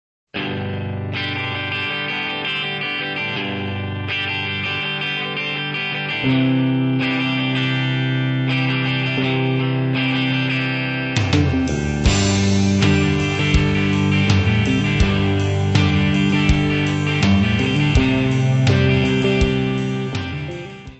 : stereo; 12 cm
Área:  Pop / Rock